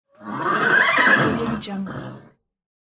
Horse Neighing Bouton sonore